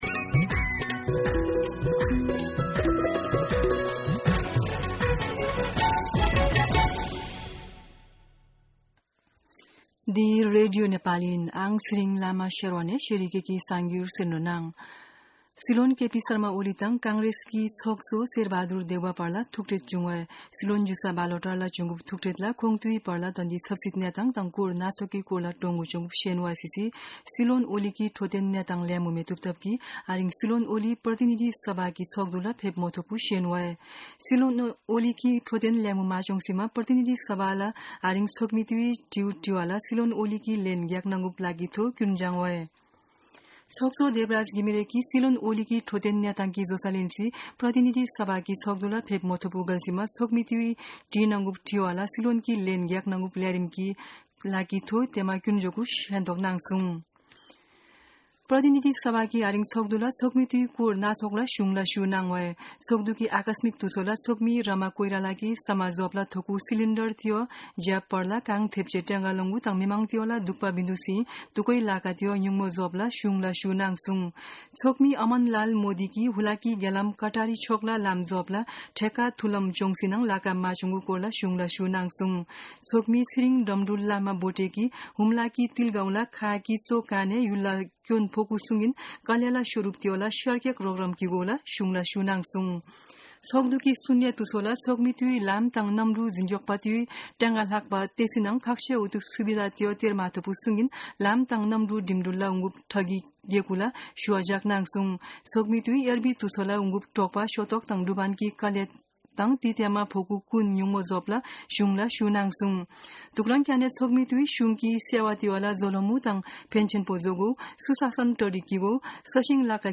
शेर्पा भाषाको समाचार : ६ जेठ , २०८२
Sherpa-News-02-06.mp3